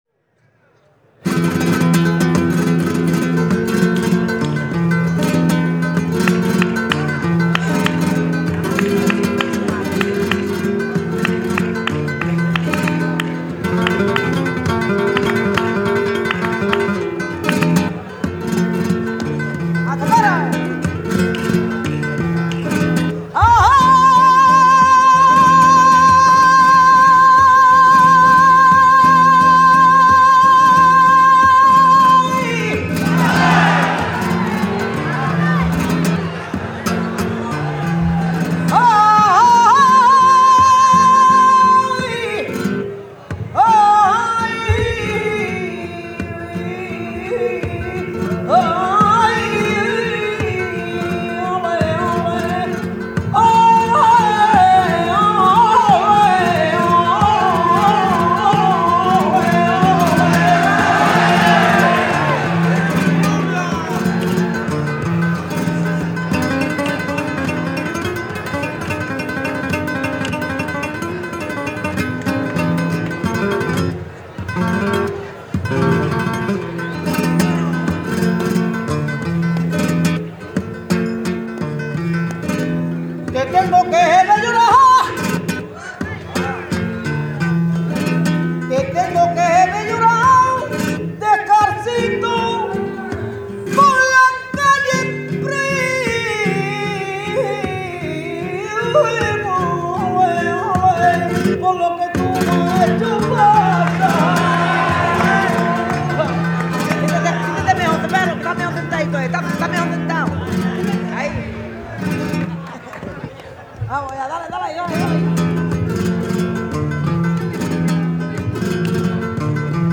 Guitare
Tangos 1